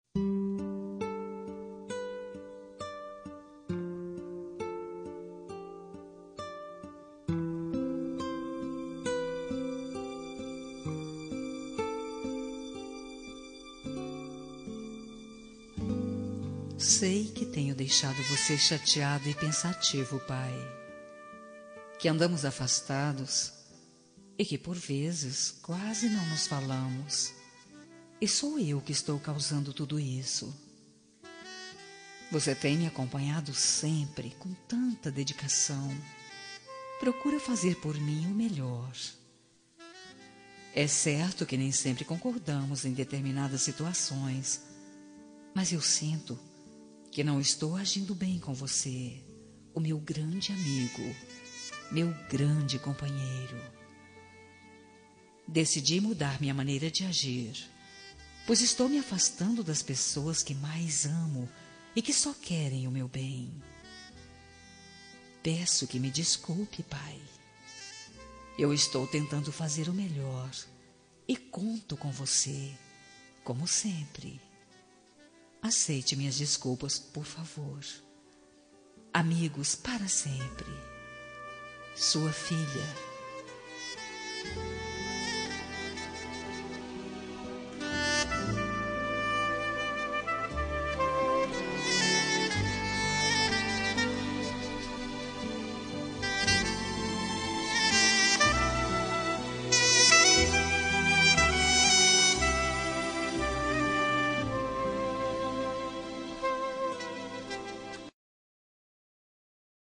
Reconciliação Familiar – Voz Feminina – Cód: 088725 – Pai